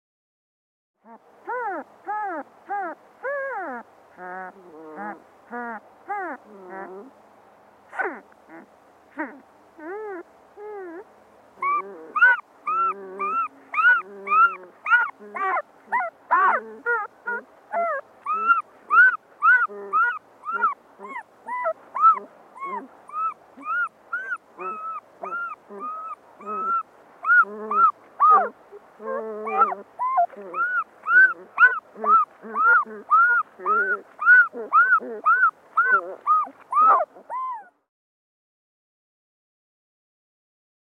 Puppies | Sneak On The Lot
Puppies; High-pitched Whine And Whimpers, Close Perspective.